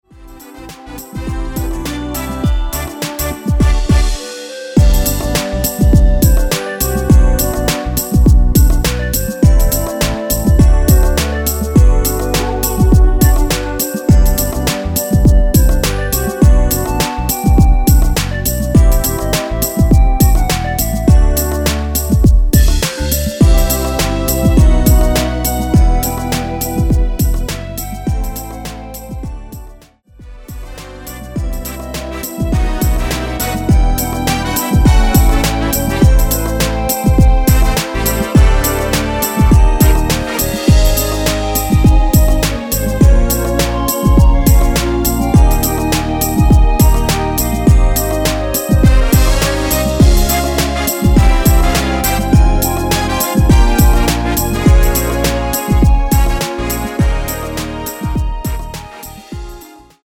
원키에서(-3)내린 멜로디 포함된 MR입니다.(미리듣기 확인)
앞부분30초, 뒷부분30초씩 편집해서 올려 드리고 있습니다.
중간에 음이 끈어지고 다시 나오는 이유는